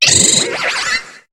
Cri de Hoopa dans Pokémon HOME.